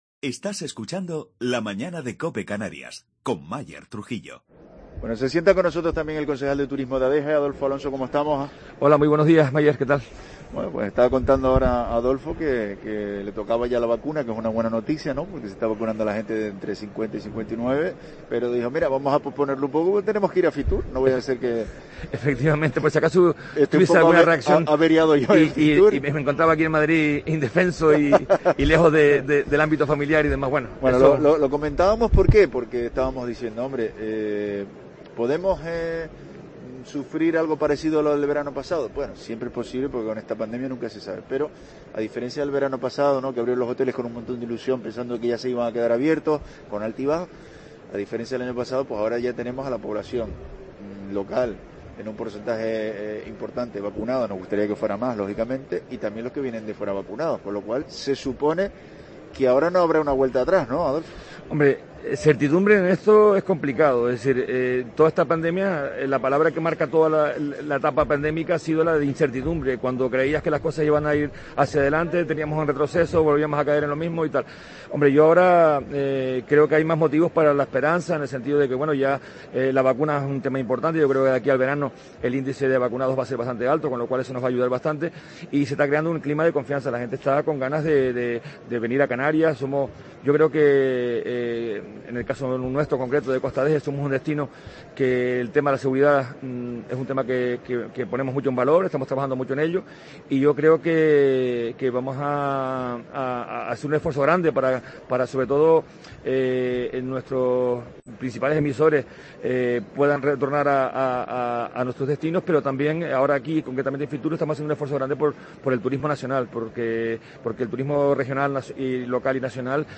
Adolfo Alonso, concejal de Turismo de Adeje